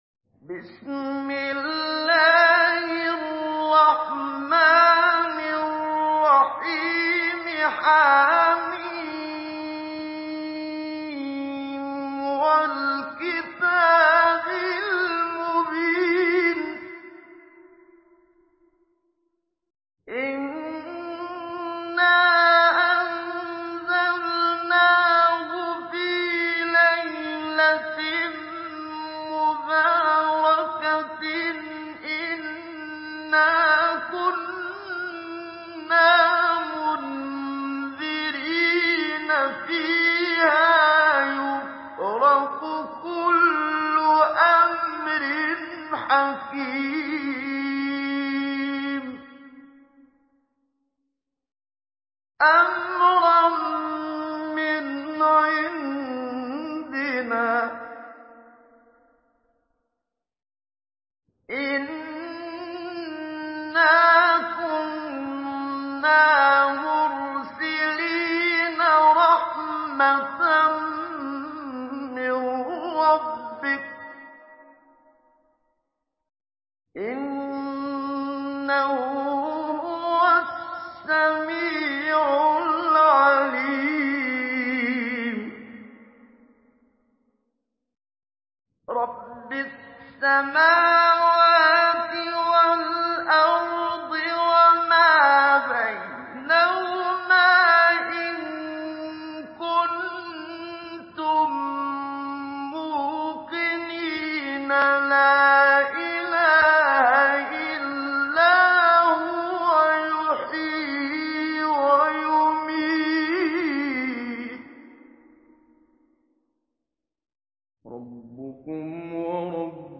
Surah الدخان MP3 in the Voice of محمد صديق المنشاوي مجود in حفص Narration
Surah الدخان MP3 by محمد صديق المنشاوي مجود in حفص عن عاصم narration.